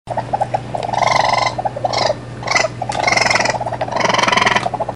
На этой странице собрана коллекция звуков, которые издают еноты.
Енот играет